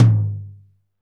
TOM P C H14R.wav